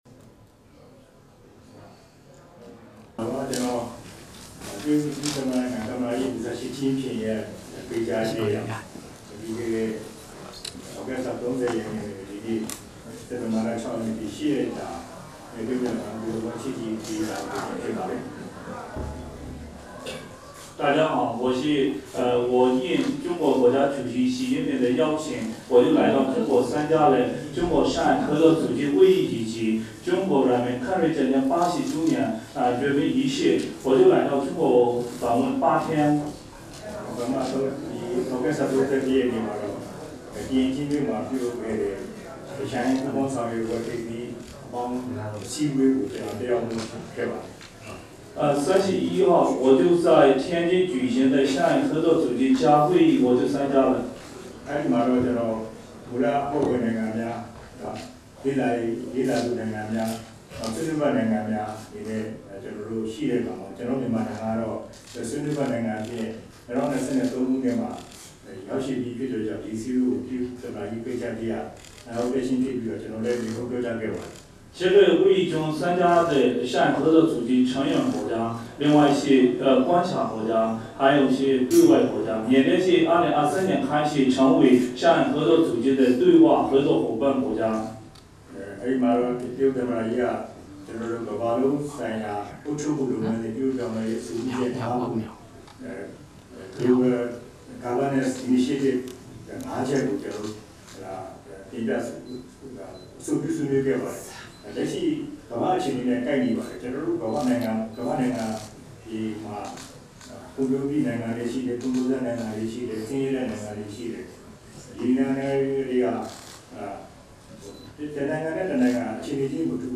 ပြည်ထောင်စုသမ္မတမြန်မာနိုင်ငံတော် ယာယီသမ္မတ နိုင်ငံတော်လုံခြုံရေးနှင့် အေးချမ်းသာယာရေးကော်မရှင်ဥက္ကဋ္ဌ ဗိုလ်ချုပ်မှူးကြီး မင်းအောင်လှိုင် ချန်ဒူးမြို့၌ မီဒီယာများ၏ မေးမြန်းမှုများအား ပြန်လည်ဖြေကြားချက်